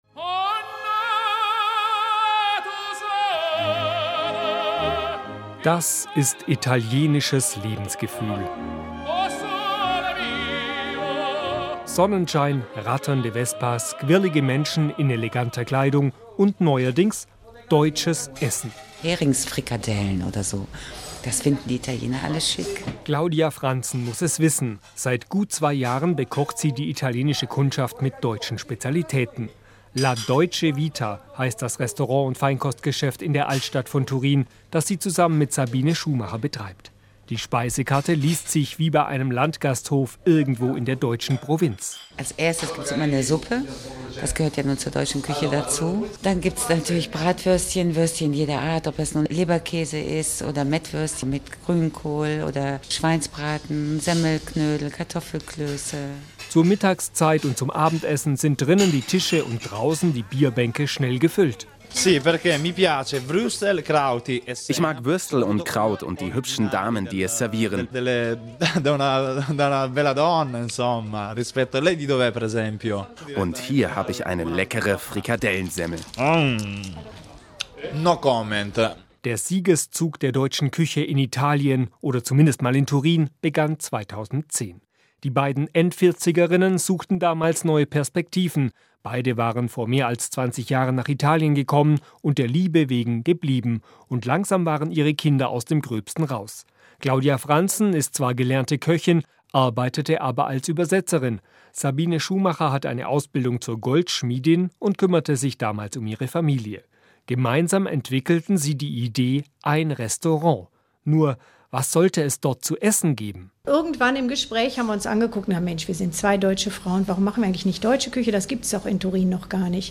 Intervista della radio tedesca "Radiobeitrags"